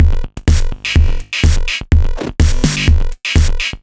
Metal Edge 01.wav